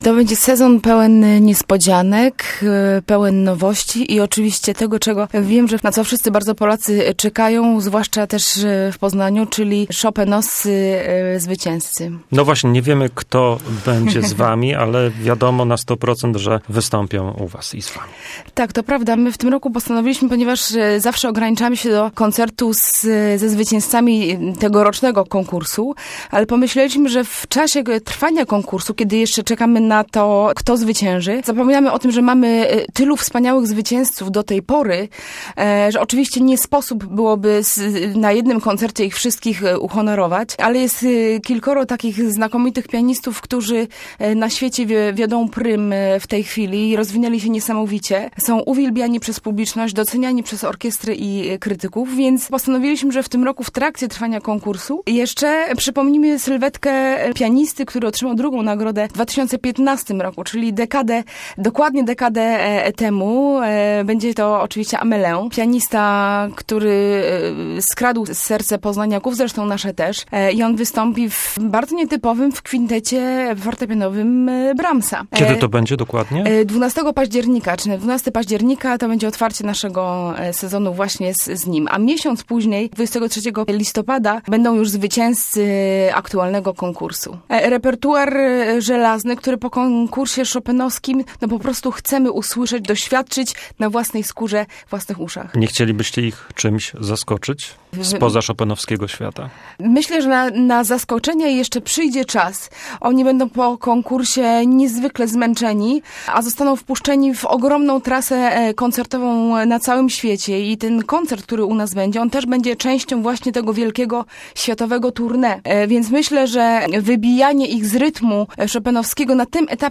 Zapi rozmowy: